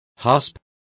Complete with pronunciation of the translation of hasps.